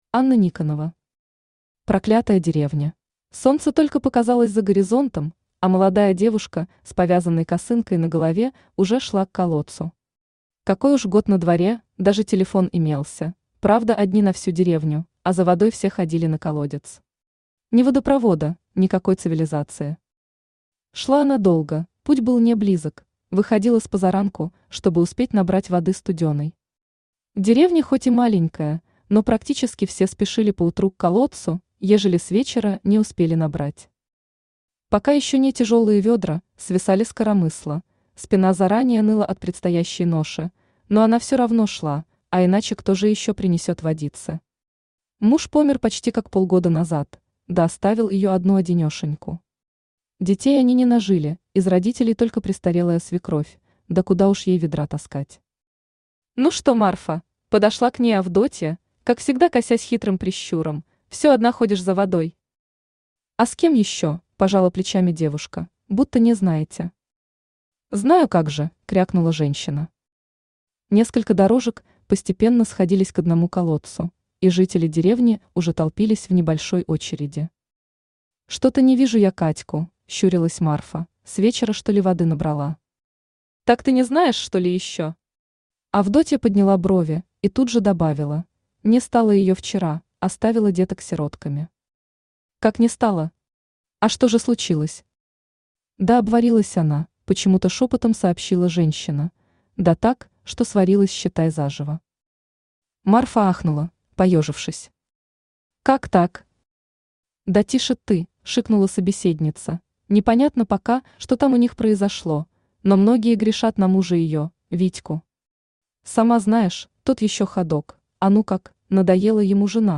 Aудиокнига Проклятая деревня Автор Анна Никонова Читает аудиокнигу Авточтец ЛитРес.